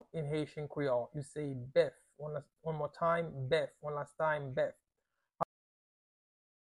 Pronunciation:
Listen to and watch “Bèf” audio pronunciation in Haitian Creole by a native Haitian  in the video below:
How-to-say-Cow-in-Haitian-Creole-Bef-pronunciation-by-a-Haitian-teacher.mp3